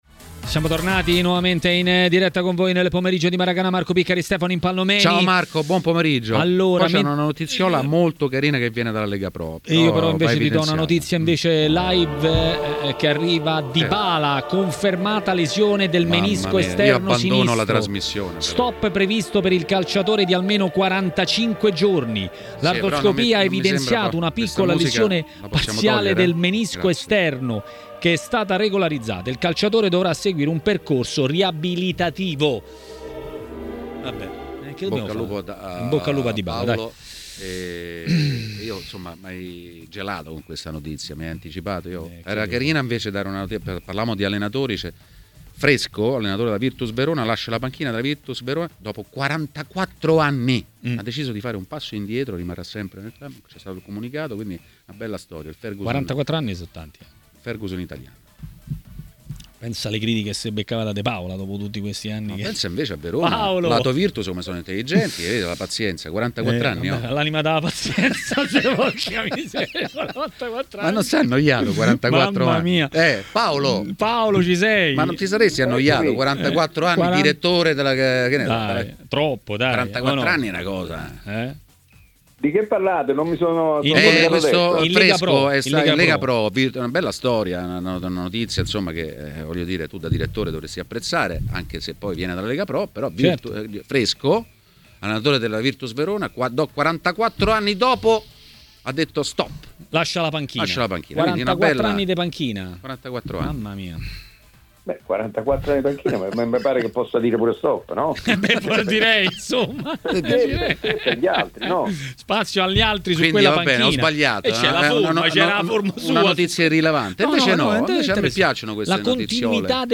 Le Interviste
L'ex portiere Simone Braglia è intervenuto a Maracanà, nel pomeriggio di TMW Radio.